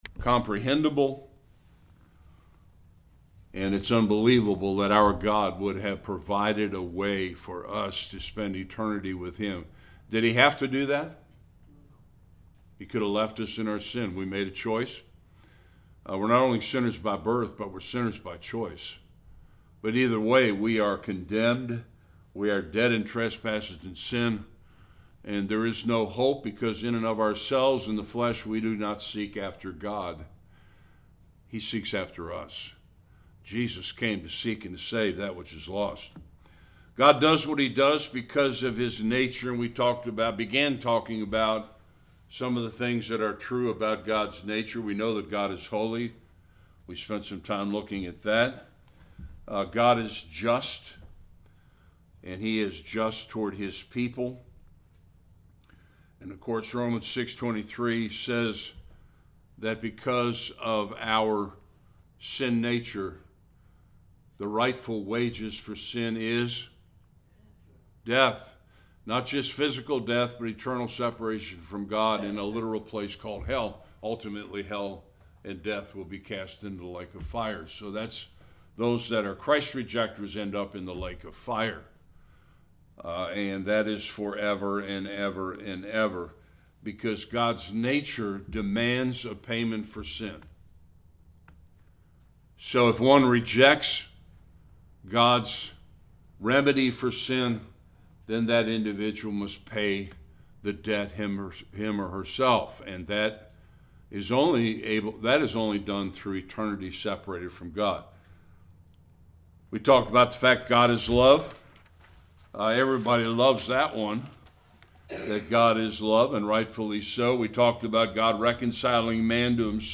Various Passages Service Type: Sunday School Continuation of a soulwinning plan.